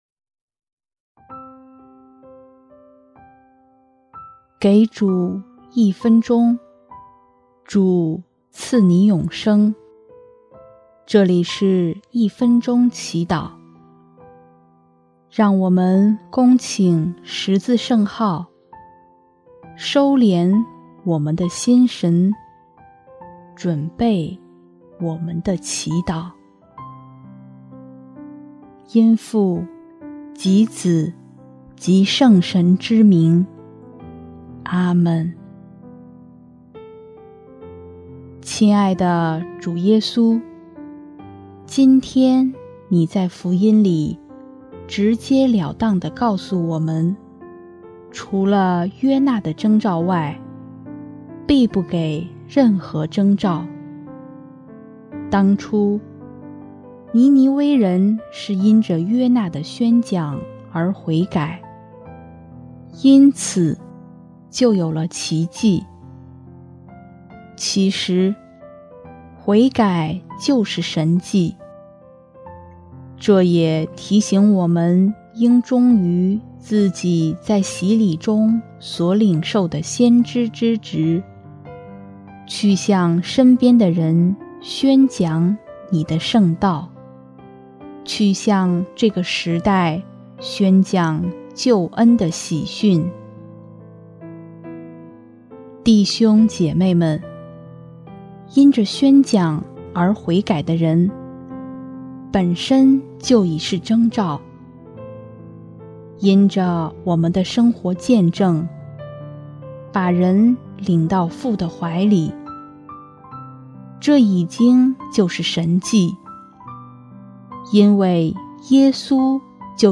音乐： 第二届华语圣歌大赛参赛歌曲《慈爱的目光》